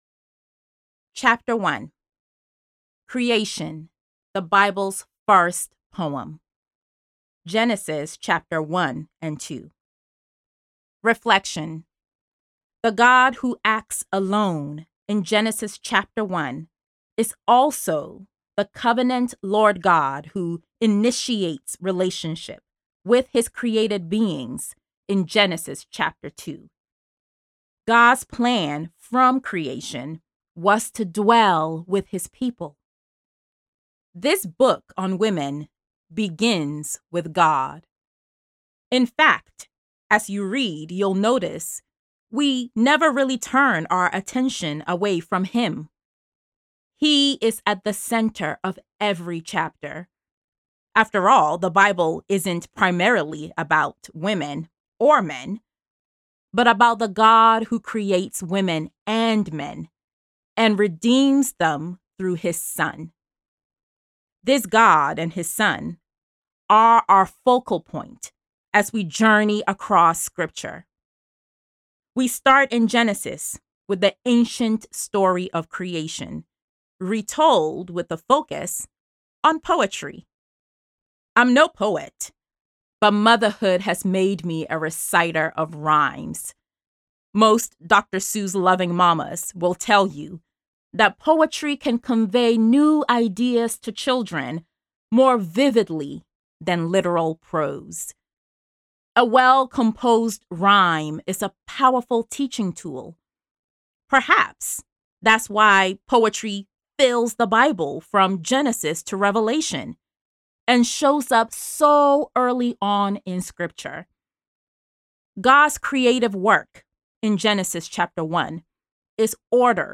(Audiobook)